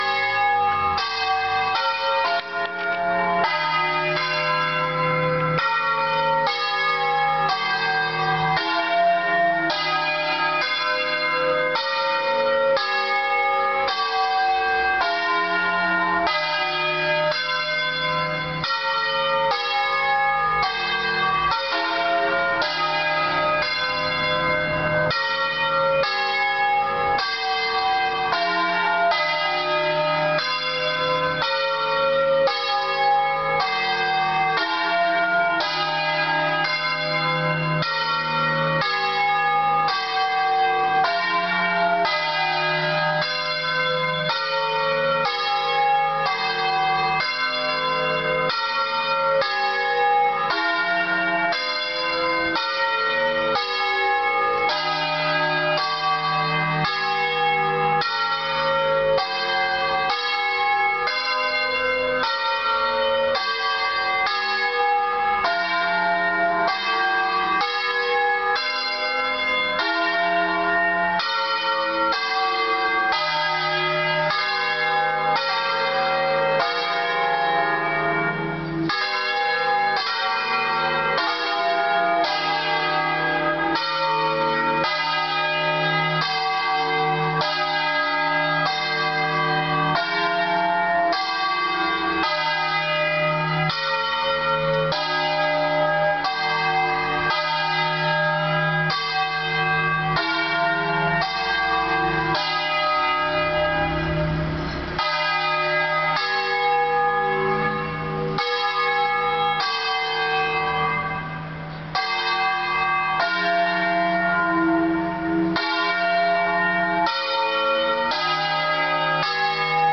Il campanile e la voce delle campane
L'attuale concerto, intonato in Mi bemolle, è pertanto costituito da sei campane disposte a sistema di movimento manuale e montate su incastellatura metallica.
campane-2.mp3